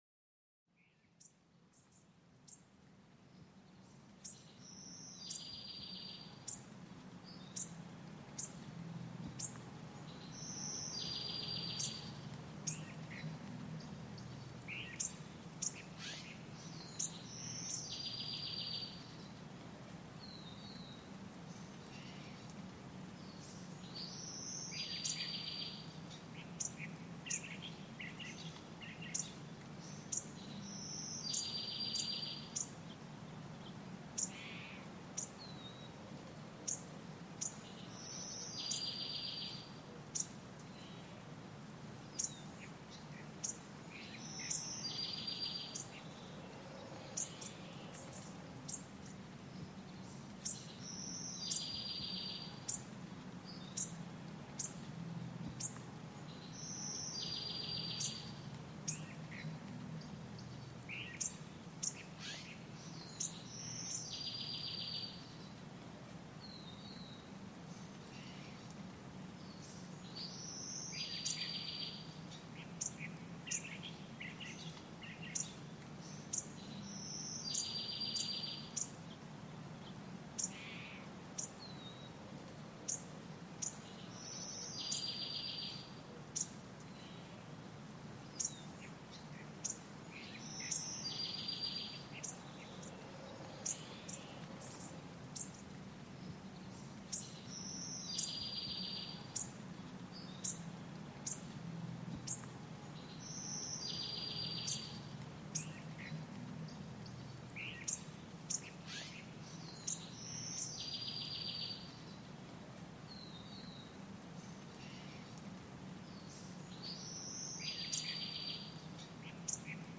Pluie Apaisante en Forêt : Détente et Relaxation
Plongez-vous dans une forêt verdoyante où la pluie tombe doucement, créant une symphonie naturelle apaisante. Les gouttes de pluie sur les feuilles et le sol apportent une sérénité parfaite pour se détendre ou s'endormir. Écoutez ce doux murmure et laissez votre esprit vagabonder en pleine nature.Ce podcast vous offre une immersion sonore unique au cœur de la nature, idéale pour la relaxation et le sommeil. Laissez-vous bercer par les sons de la pluie, du vent, et des animaux pour un moment de paix intérieure.